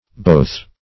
Both \Both\ (b[=o]th), a. or pron.